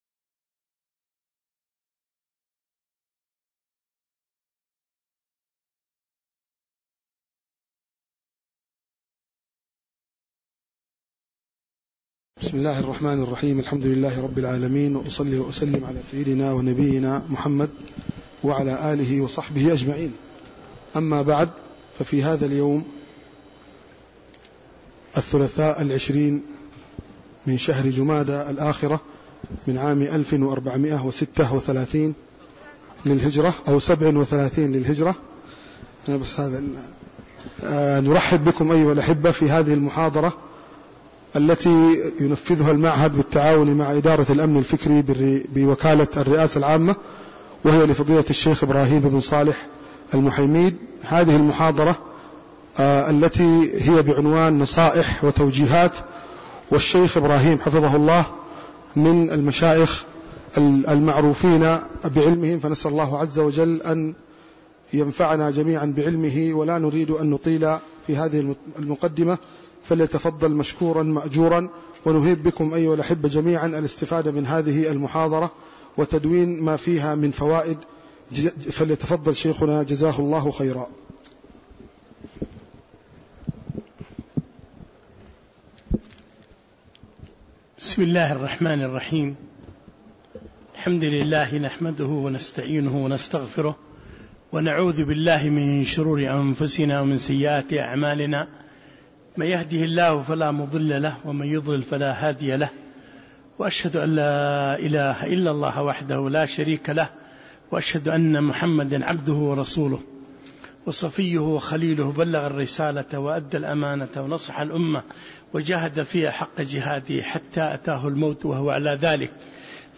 محاضرة - توجيهات وإرشادات